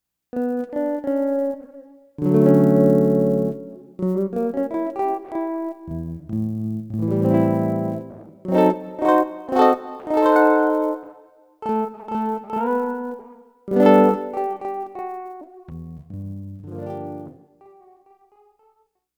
hmmmm... envelope control of a chorus
As you dig in the rate goes up and the width goes down to somewhat compensate the warble: